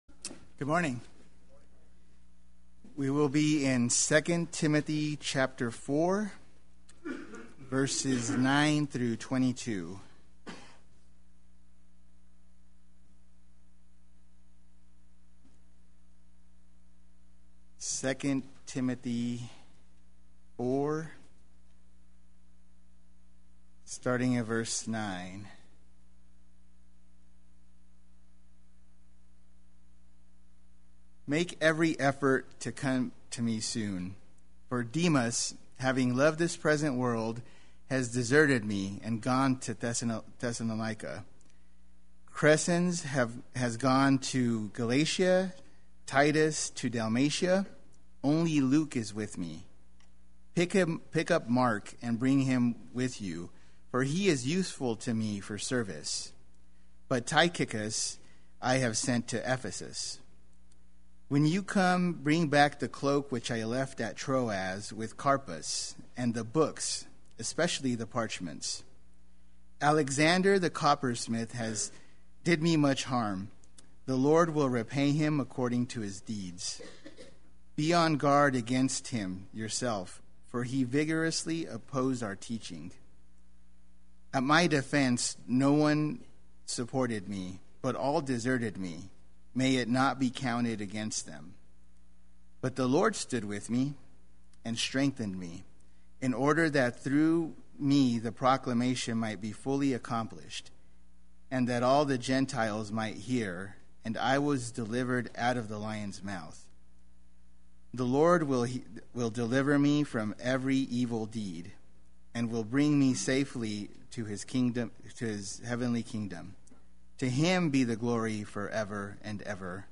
Play Sermon Get HCF Teaching Automatically.
Amen Sunday Worship